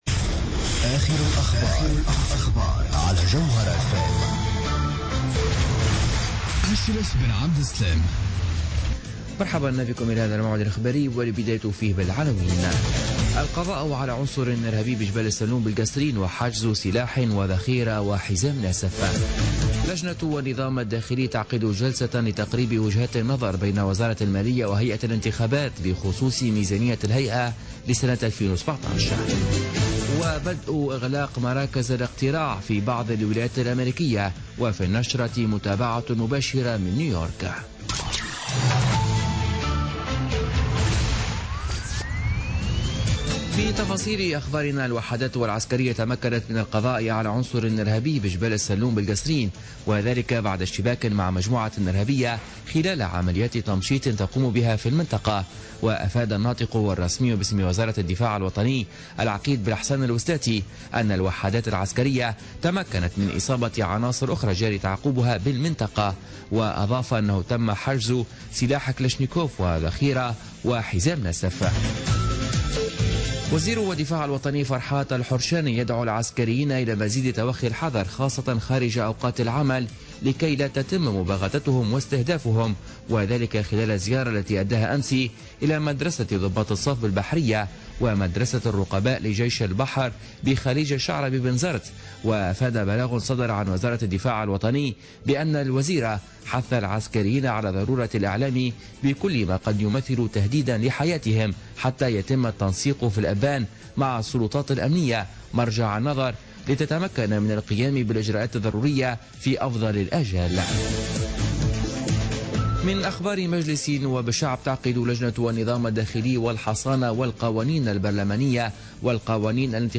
نشرة أخبار منتصف الليل ليوم الاربعاء 9 نوفمبر 2016